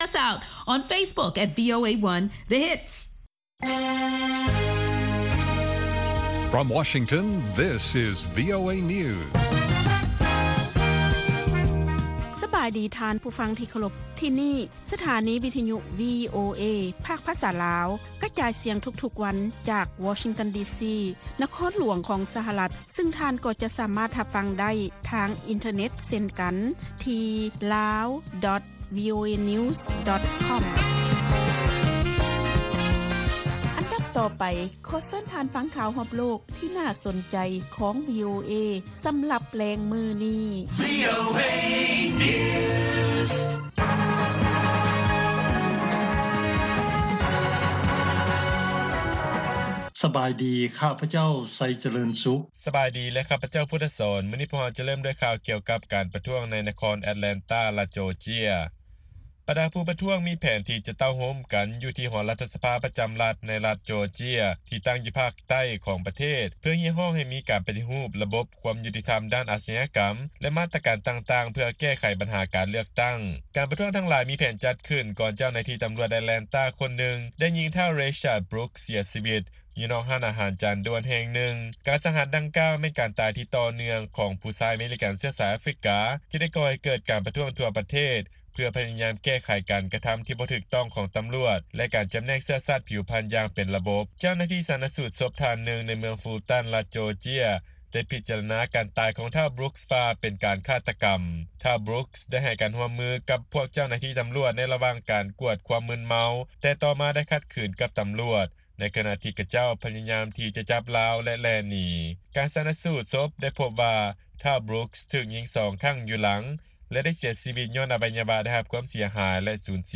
ວີໂອເອພາກພາສາລາວ ກະຈາຍສຽງທຸກໆວັນ.